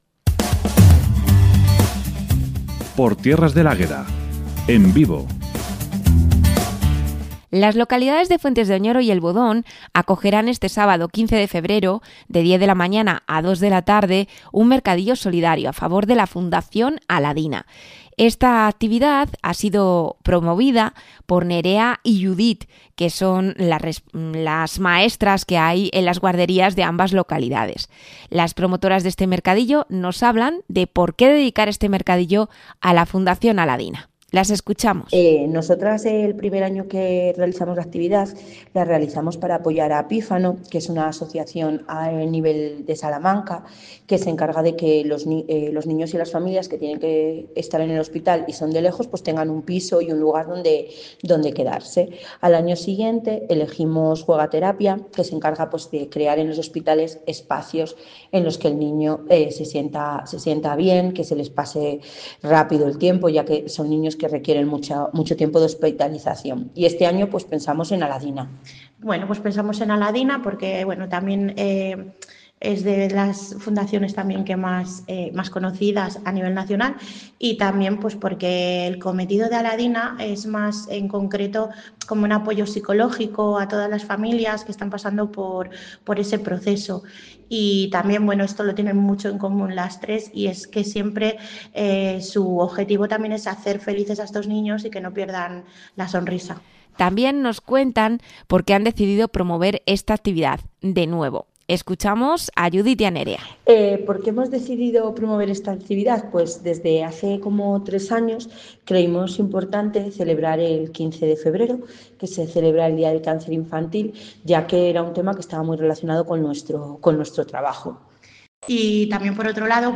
(AUDIO ENTREVISTA) Todo preparado para los mercadillos solidarios en El Bodón y Fuentes de Oñoro - Noticias Ciudad Rodrigo